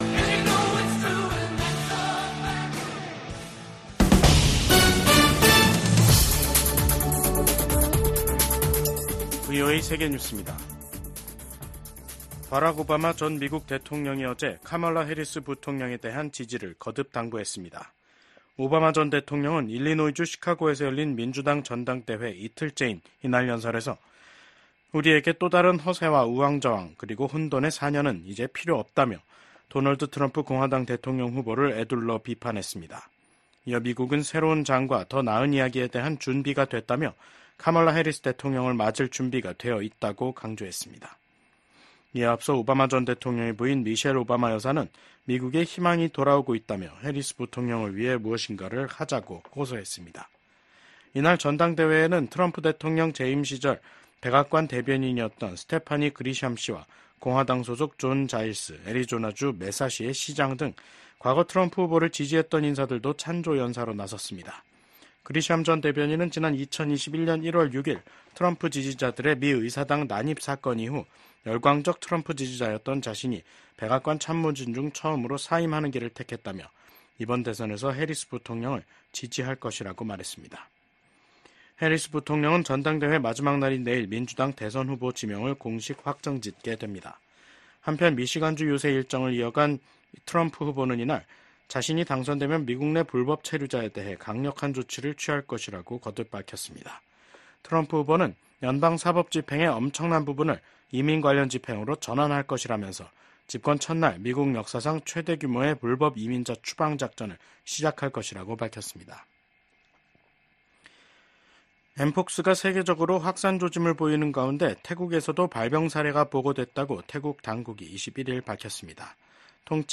VOA 한국어 간판 뉴스 프로그램 '뉴스 투데이', 2024년 8월 21일 3부 방송입니다. 미국 국방부는 미한 연합훈련인 을지프리덤실드 연습이 방어적 성격이란 점을 분명히 하며 ‘침략 전쟁 연습’이란 북한의 주장을 일축했습니다.